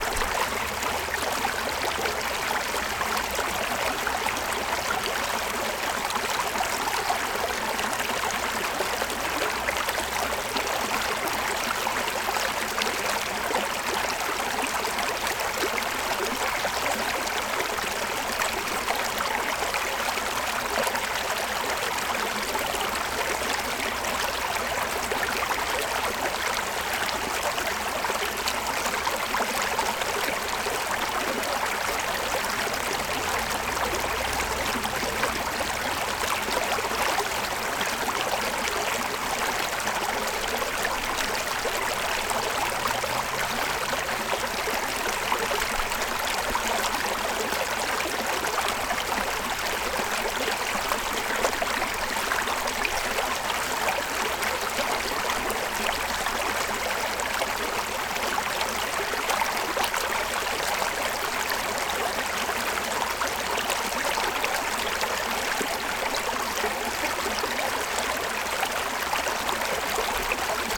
River
Category 🎵 Relaxation
brook creek flow flowing liquid relaxing river stream sound effect free sound royalty free Memes